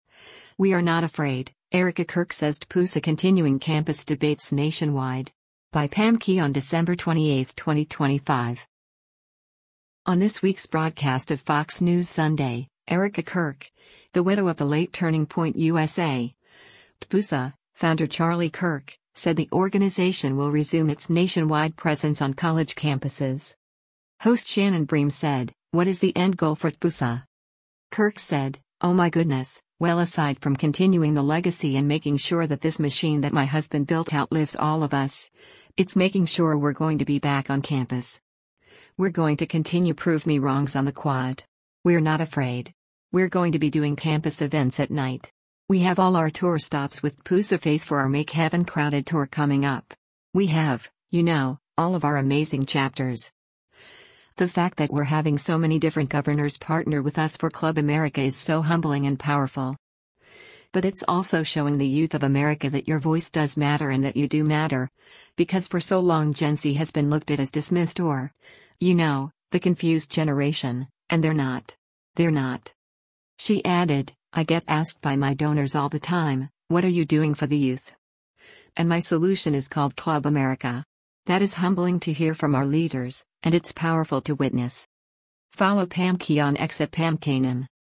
On this week’s broadcast of “Fox News Sunday,” Erika Kirk, the widow of the late Turning Point USA (TPUSA) founder Charlie Kirk, said the organization will resume its nationwide presence on college campuses.
Host Shannon Bream said, “What is the end goal for TPUSA?”